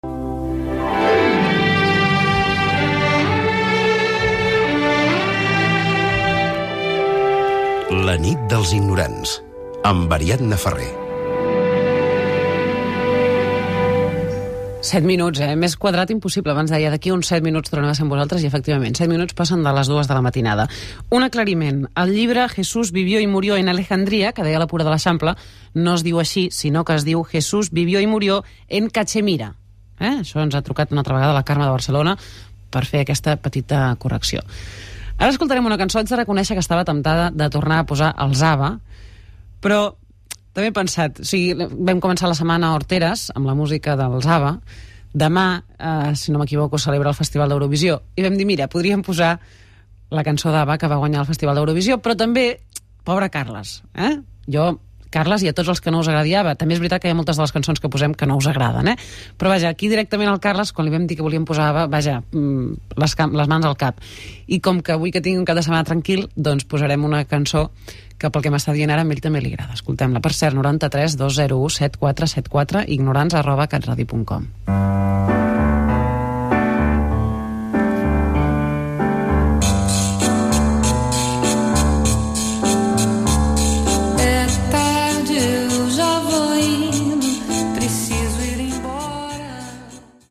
Identificació del programa, hora, aclariment del títol d'un llibre, telèfon i tema musical